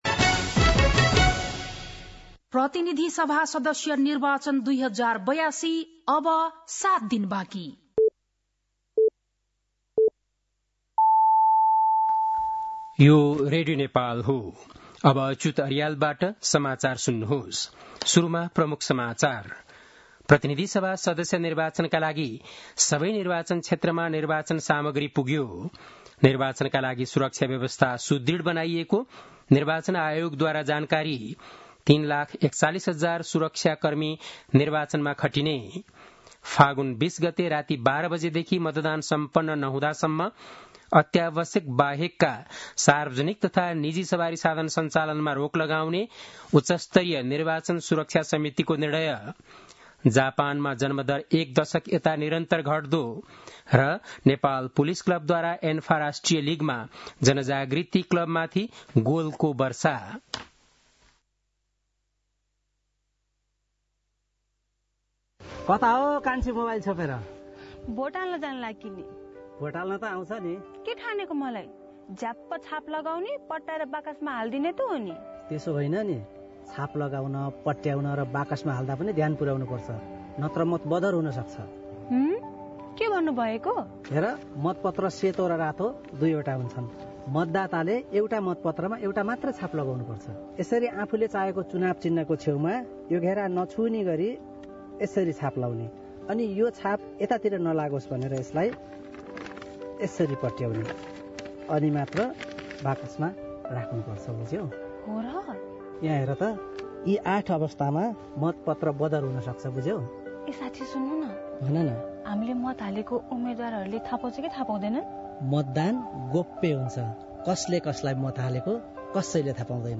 बेलुकी ७ बजेको नेपाली समाचार : १४ फागुन , २०८२
7-pm-nepali-news-11-14.mp3